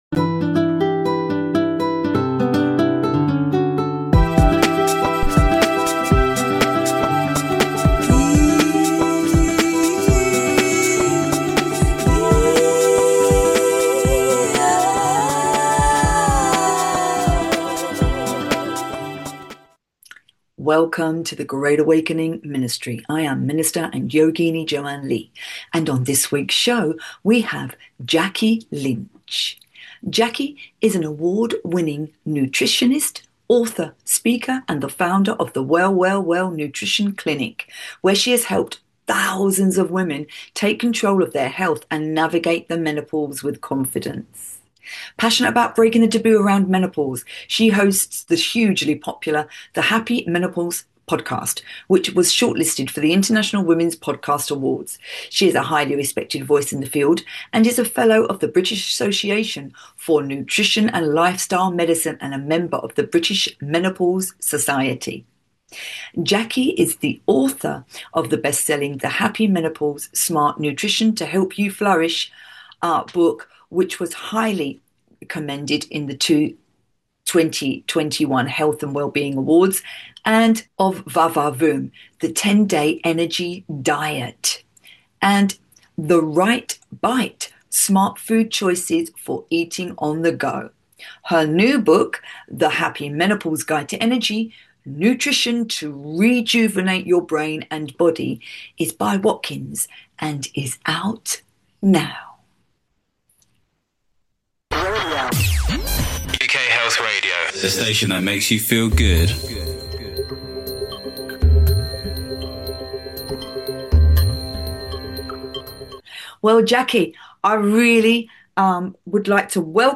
Every show has awakening chats and interviews with incredible people from all around the world; light-workers, way-showers, truth speakers, earth keepers, love embracers, healers and therapists, and all those who are benefiting others and our planet in some way.